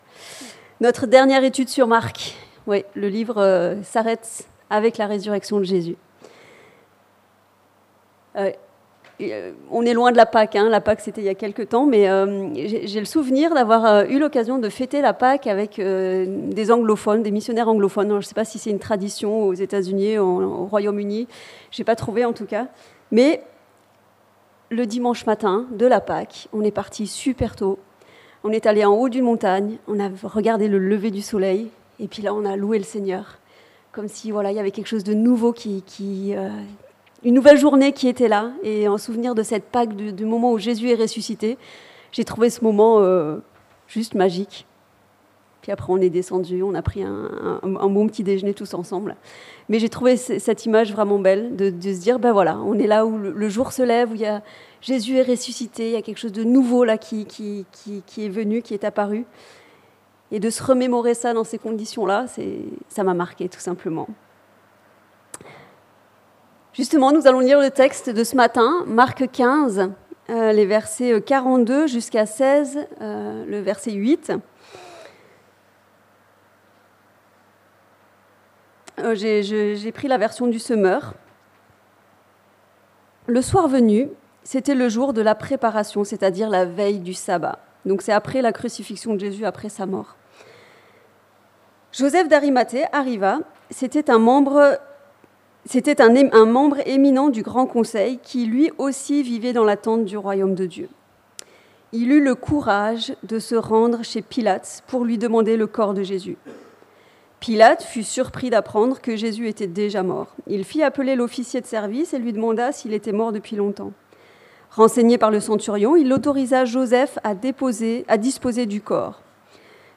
Quelles réactions face à la mort et la résurrection de Jésus ? - Prédication de l'Eglise Protestante Evangélique de Crest